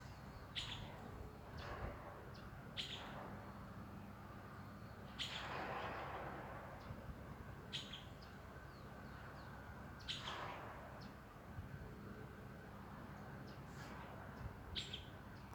Myiodynastes maculatus solitarius
Nombre en español: Benteveo Rayado
Nombre en inglés: Streaked Flycatcher
Localidad o área protegida: Concordia
Condición: Silvestre
Certeza: Vocalización Grabada
Benteveo-rayado-2_1.mp3